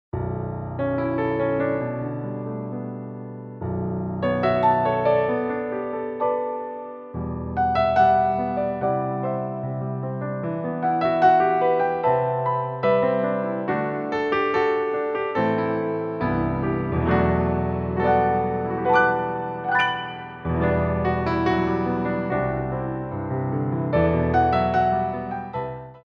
for Ballet Class
Warm Up
4/4 (16x8)